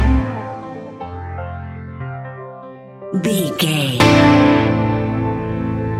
Ionian/Major
A♭
ambient
electronic
new age
downtempo
synth
pads